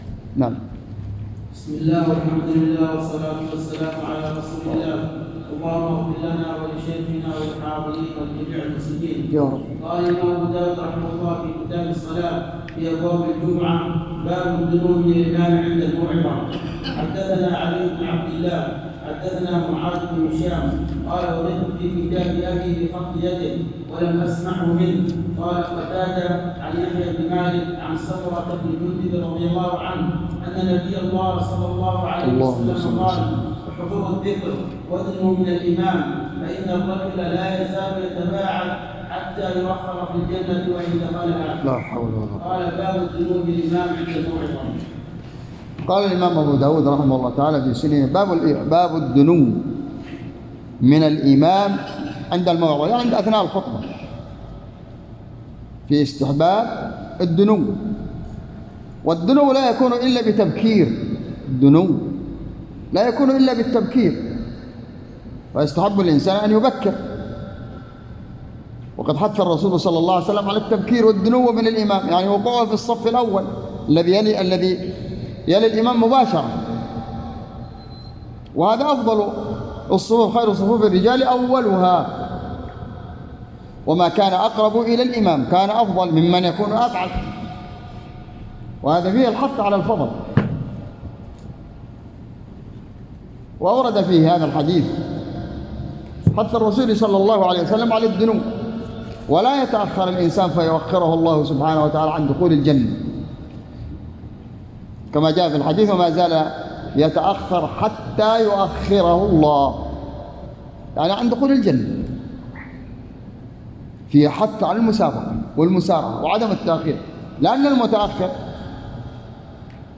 تسجيل لدروس شرح كتاب الجمعة - سنن أبي داود  _ بجامع الدرسي بصبيا